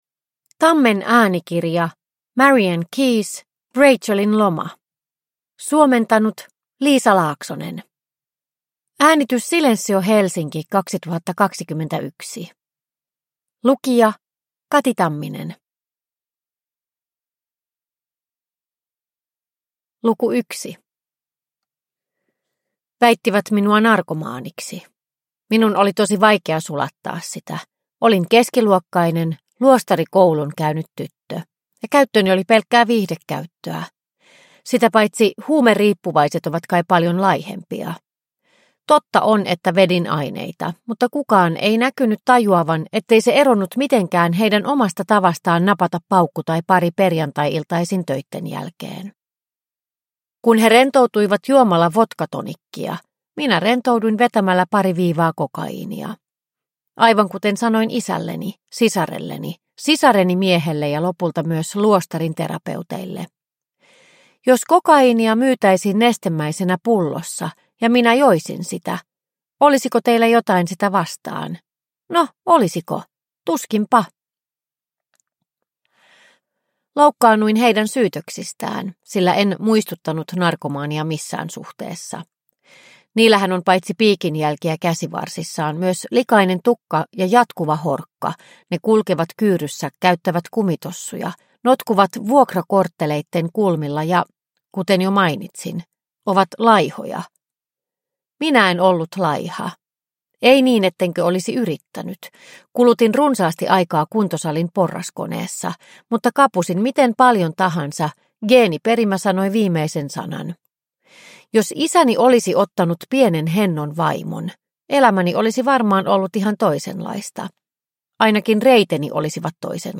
Rachelin loma – Ljudbok – Laddas ner